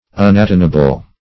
Search Result for " unatonable" : The Collaborative International Dictionary of English v.0.48: Unatonable \Un`a*ton"a*ble\, a. 1. Not capable of being brought into harmony; irreconcilable.
unatonable.mp3